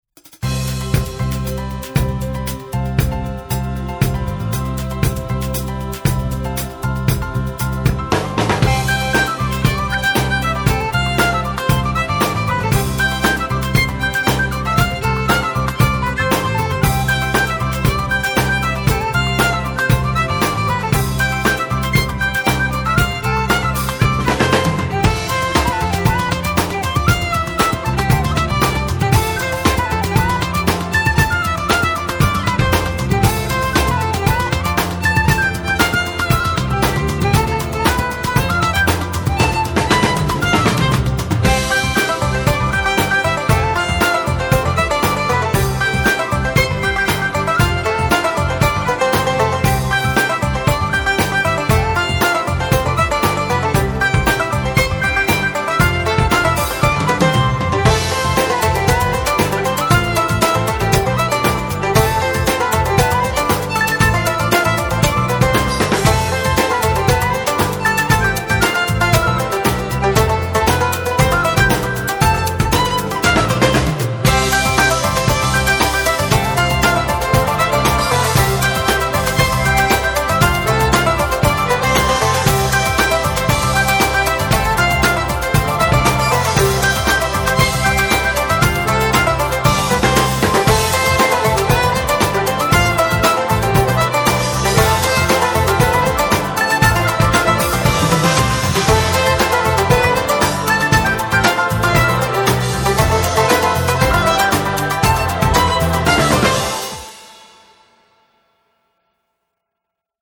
Description : Irish Linedance 1 mur 32 temps